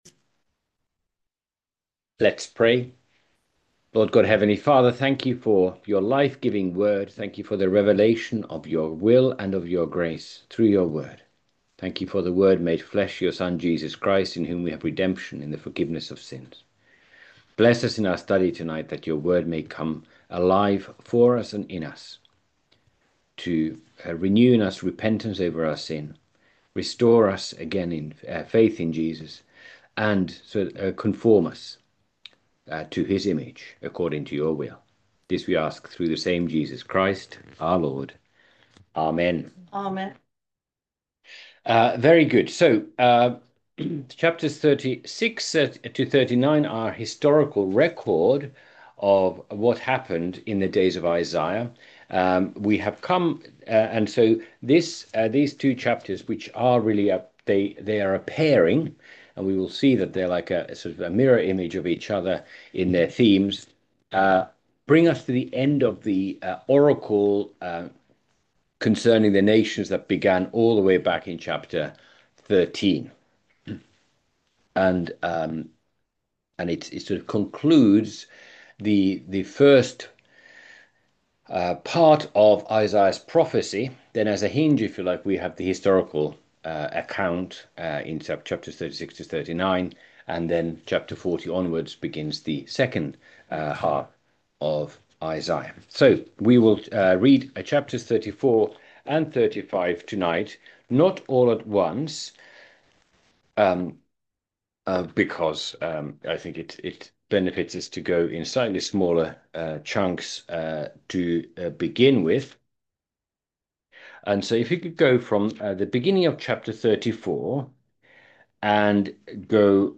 Isaiah Date: 4 September 2025 Chapters 34:1-35:10 Unfortunately, owing to a technical fault, only the first 10 minutes of the study was recorded.
by admin | Sep 4, 2025 | Bible Studies, Isaiah